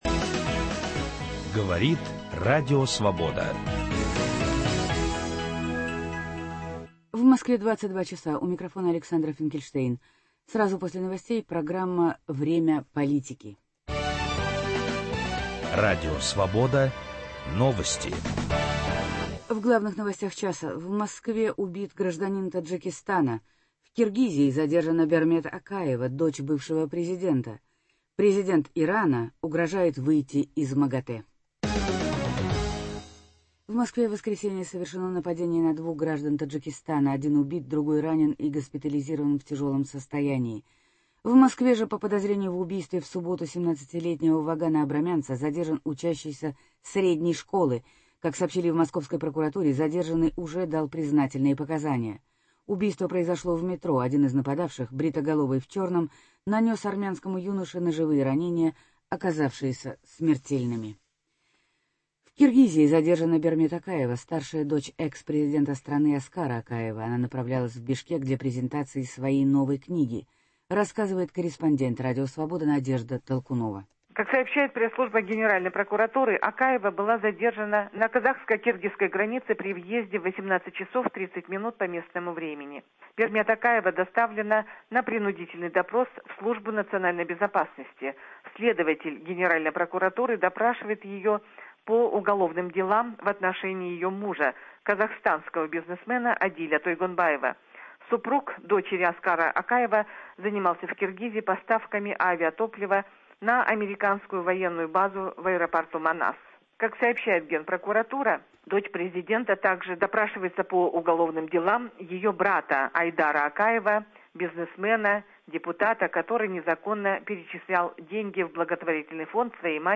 Репортаж из Лондона с Российского экономического форума. Конференция по проблемам российской региональной прессы.